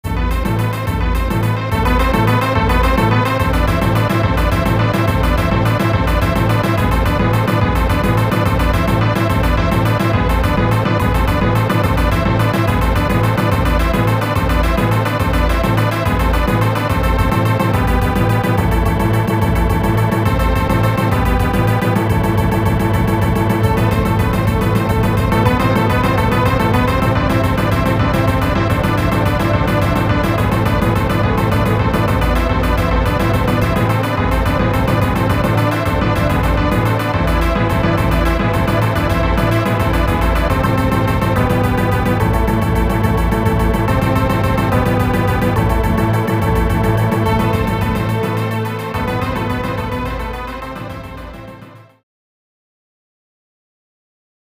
High-quality mp3s that are identical to the game's music.
The boss music.